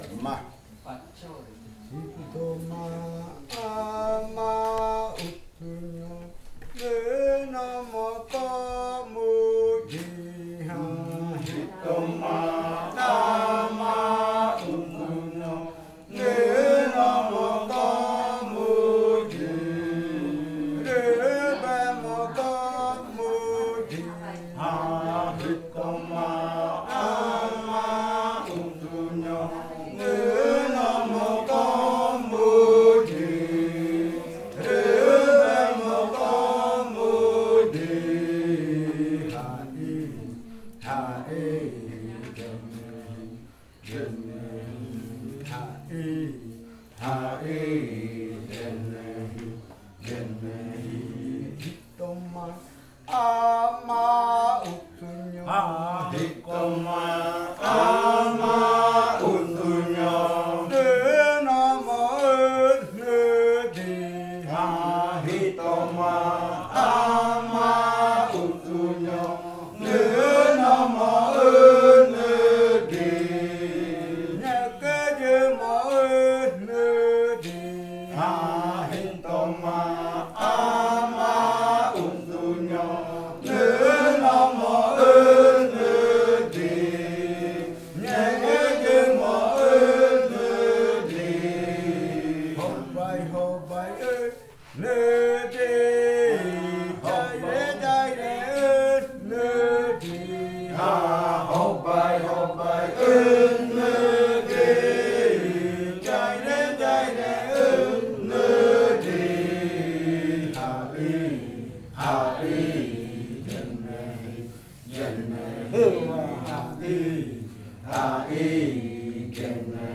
Canto de la variante jaiokɨ
Leticia, Amazonas
con el grupo de cantores sentado en Nokaido.
with the group of singers seated in Nokaido.